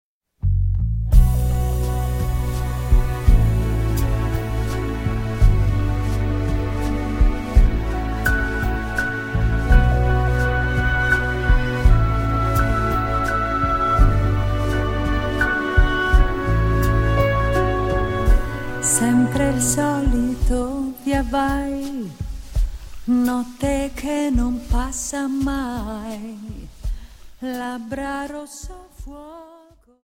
Dance: Slow Waltz 28